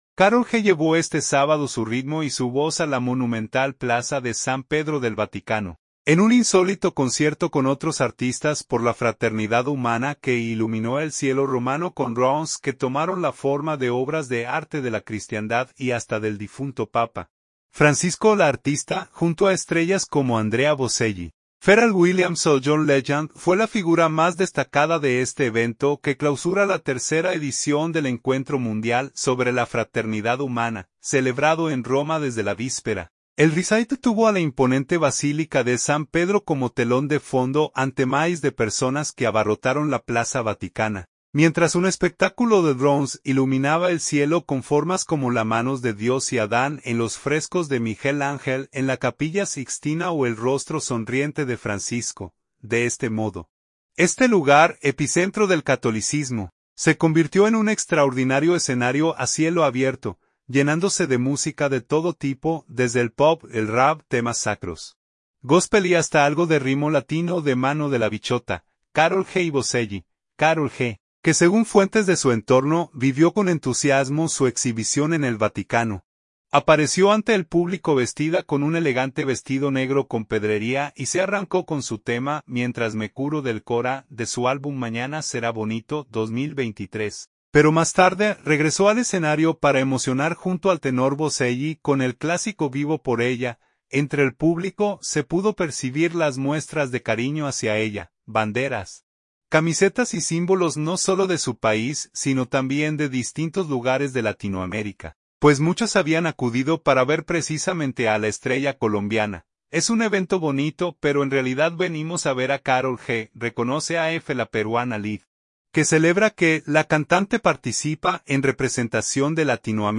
tenor
Góspel, rap y reivindicaciones